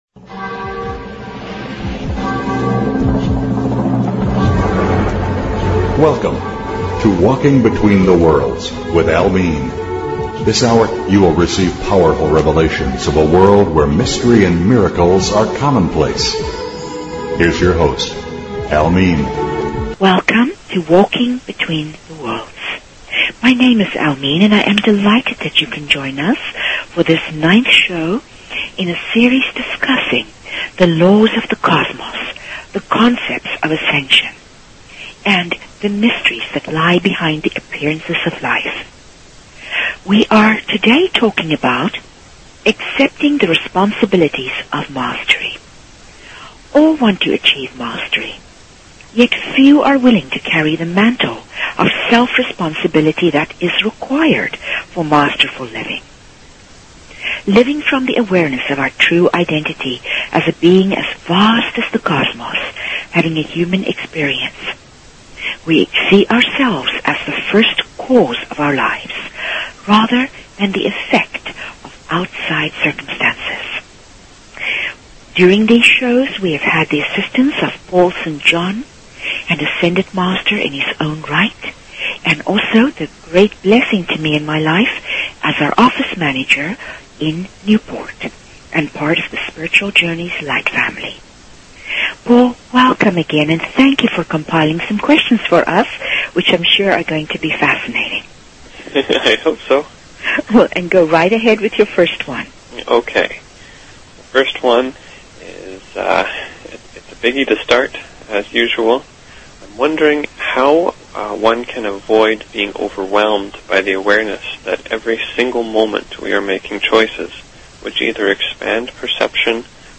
Talk Show Episode, Audio Podcast, Opening_the_Doors_of_Heaven and Courtesy of BBS Radio on , show guests , about , categorized as